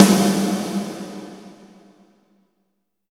84 VERB SN-L.wav